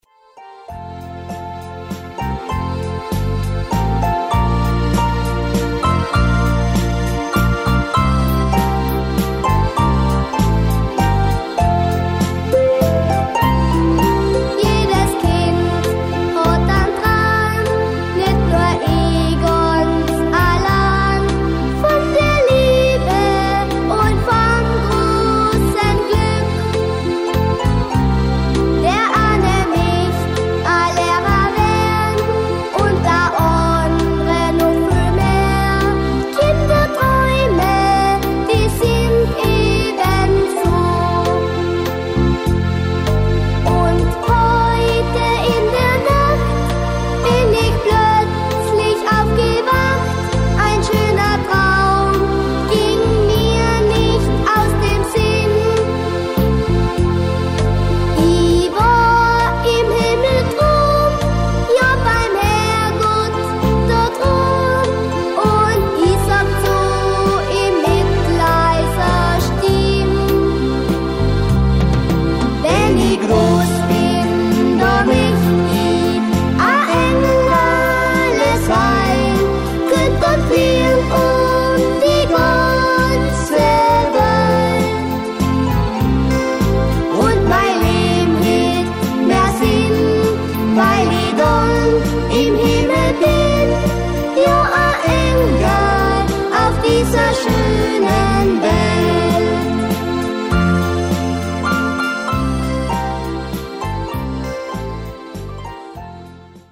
Modern